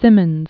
(sĭməndz, sīməndz), John Addington 1840-1893.